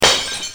breakingglass1.wav